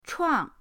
chuang4.mp3